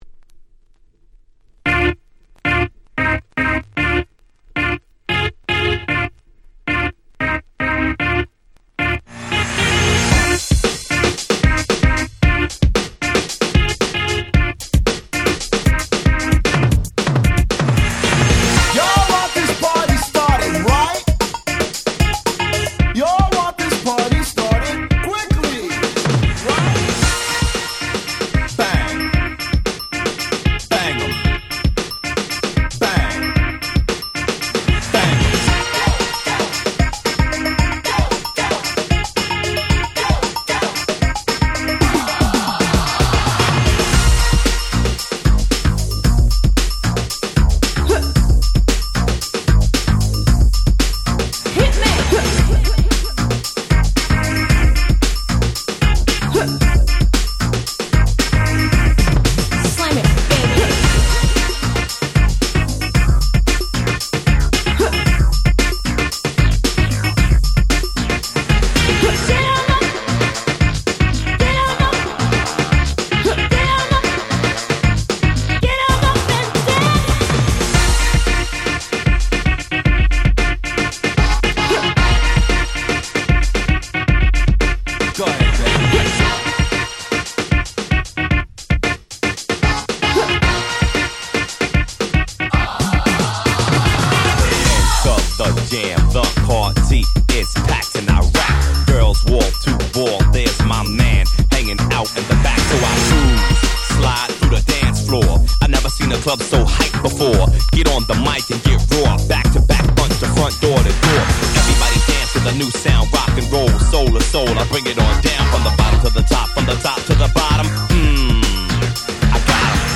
91' Super Hit Dance Music !!